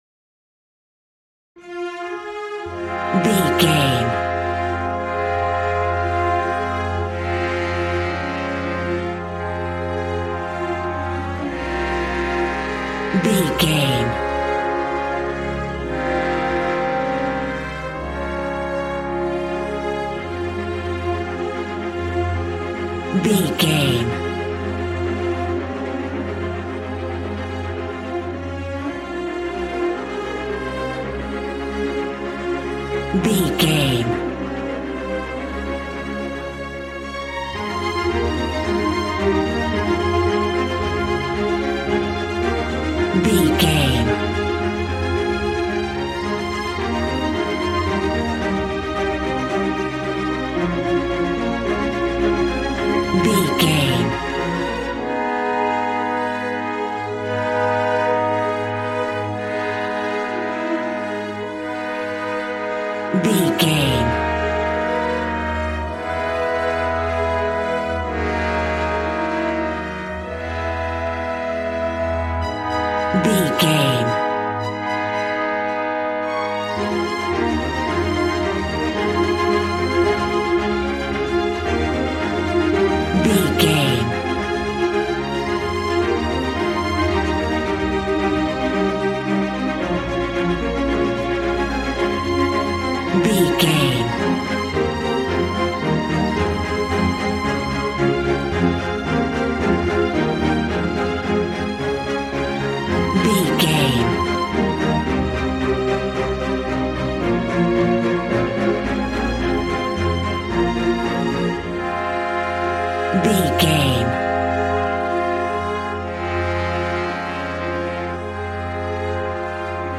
A classical music mood from the orchestra.
Regal and romantic, a classy piece of classical music.
Aeolian/Minor
regal
cello
violin
strings